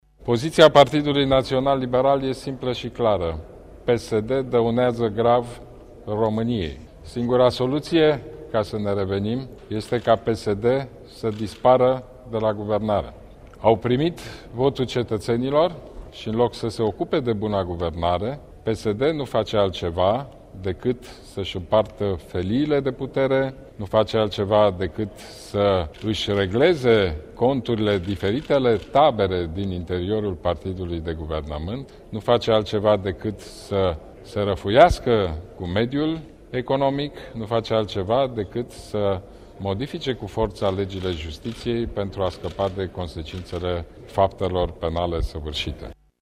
Noua criză politică declanşată de PSD va influenţa negativ viaţa tuturor românilor şi va afecta imaginea ţării noastre în exterior, a afirmat, luni, preşedintele PNL, Ludovic Orban :